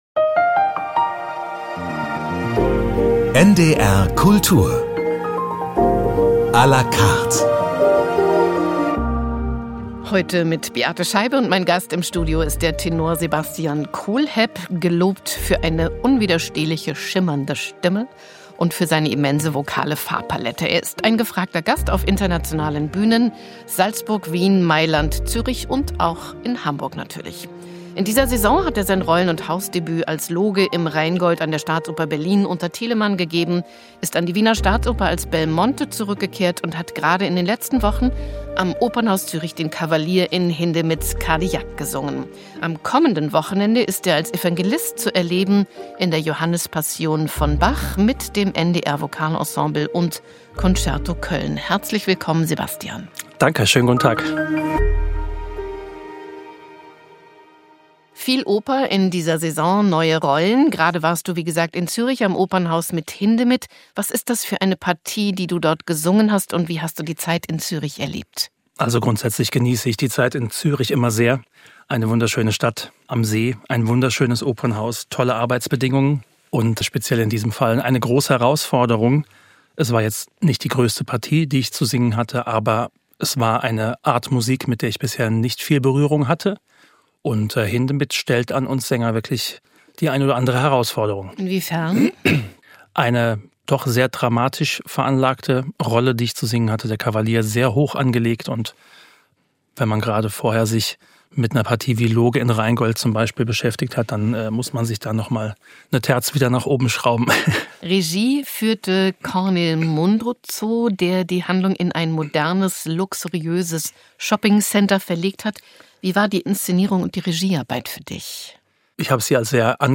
Der international gefragte Tenor Sebastian Kohlhepp besetzt in Bachs Johannes-Passion die Rolle des erzählenden Evangelisten. Oft schon hat er diese Partie gesungen, eine Rolle, die ihn auch geprägt hat.
Arie (Tenor)